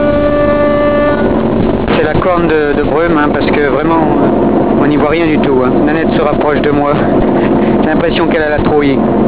Je l'attache au fond du navire, à une échelle métallique. Elle n'est pas très à l'aise. Je pense qu'elle a la trouille.